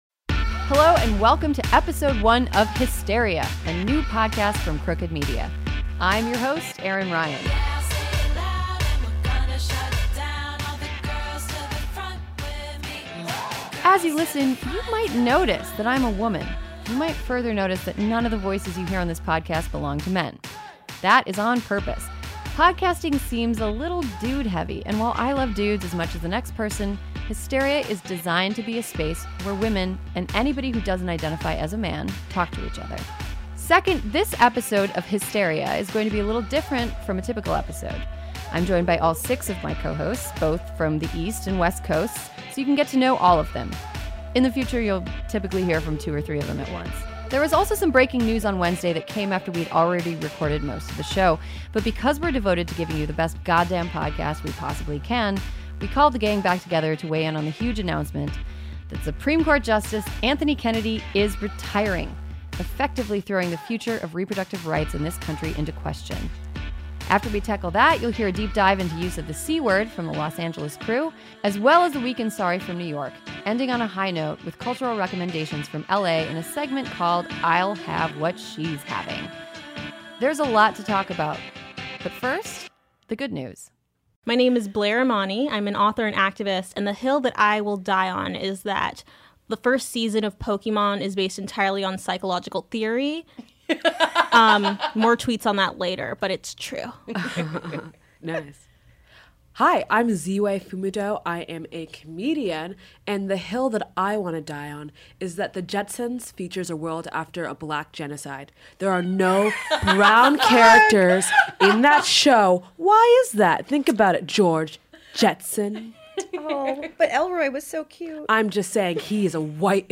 In this episode, we meet all six of our bicoastal squad of cohosts to discuss the Anthony Kennedy retirement announcement, as well as civility and other c-words. Then, the squad judges insincere public apologies and offers recommendations for cool stuff women are doing and making.